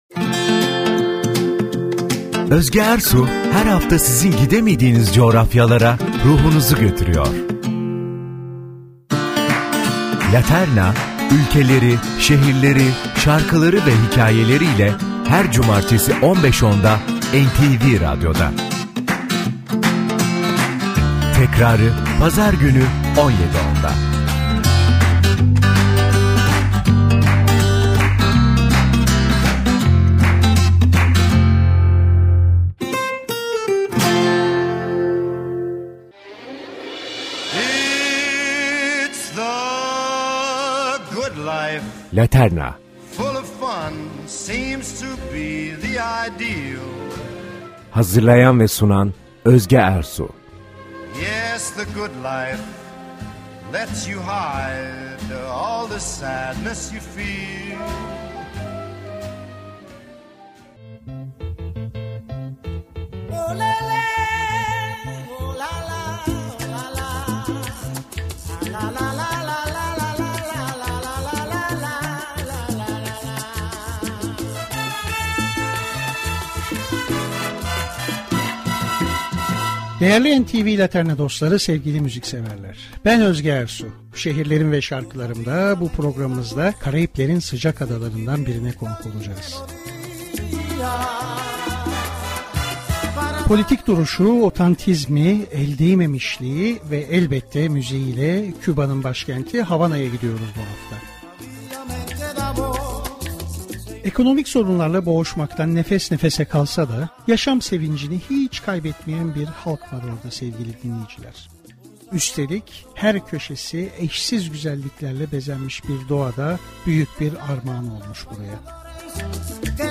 GİDEMEDİĞİNİZ YERLERE VE YAŞAMADIĞINIZ ZAMANLARA RUHUNUZU GÖTÜREN TÜRKİYENİN EN SEVİLEN BOL ÖDÜLLÜ MÜZİKAL BELGESELİ